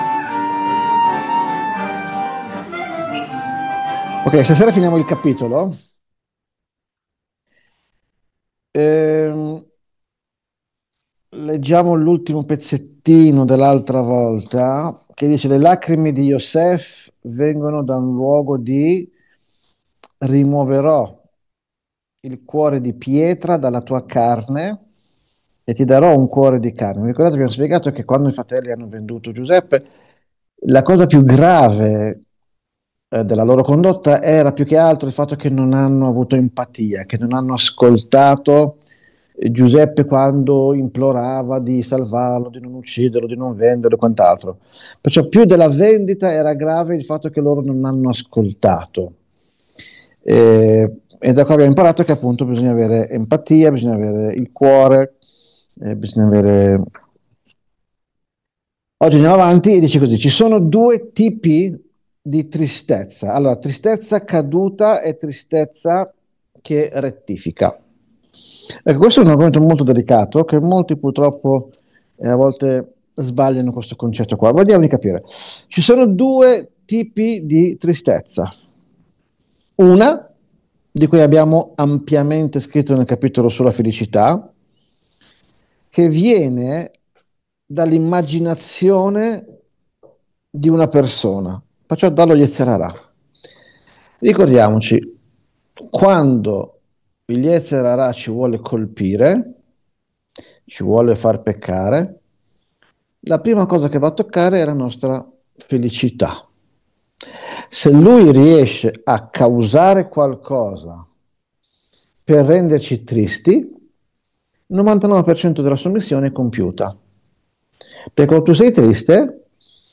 Lezione